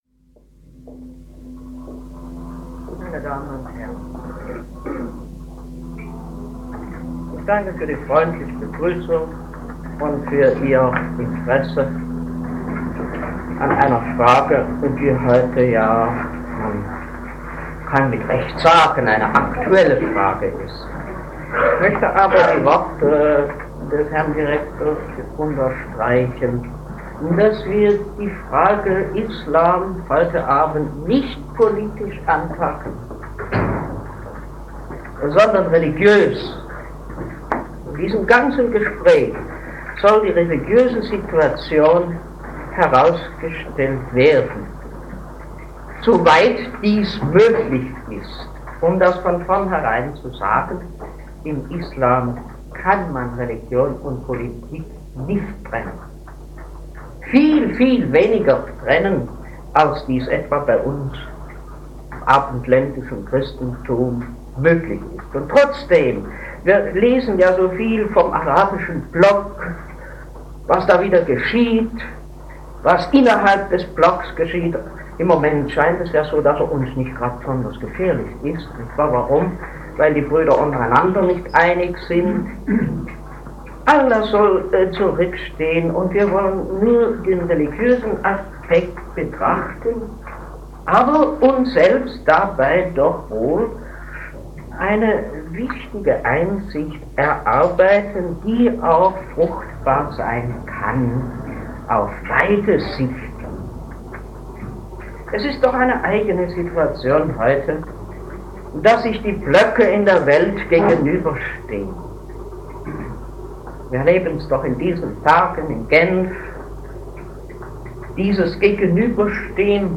Der Islam in seiner äußeren Erscheinung - Rede des Monats - Religion und Theologie - Religion und Theologie - Kategorien - Videoportal Universität Freiburg